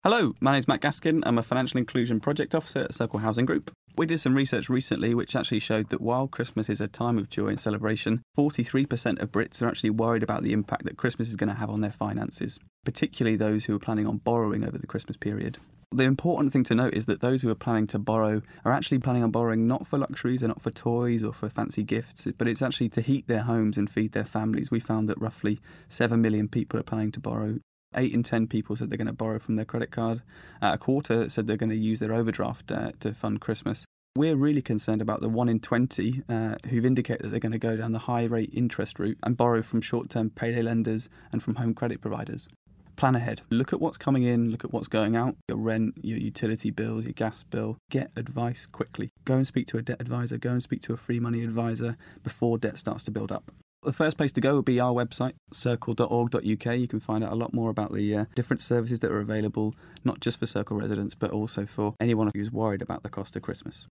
joins us in the studio to discuss The Cost Of Christmas